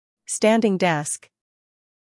Standing Desk Botão de Som